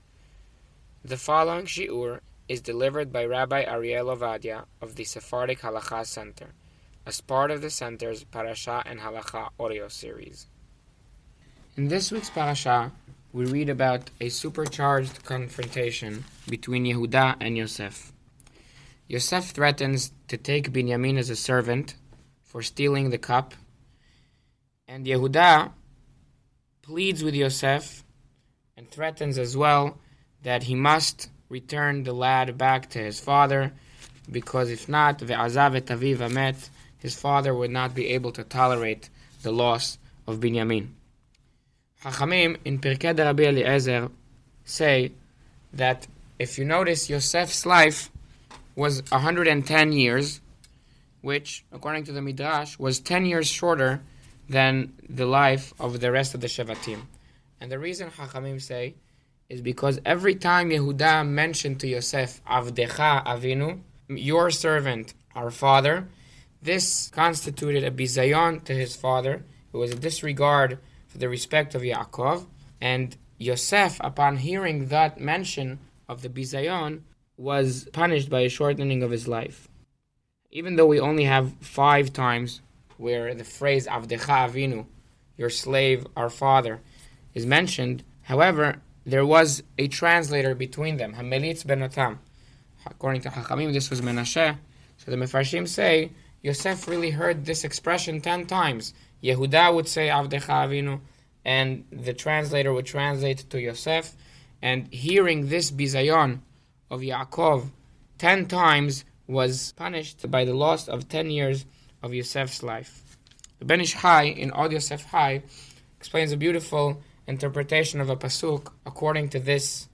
An Audio Shiur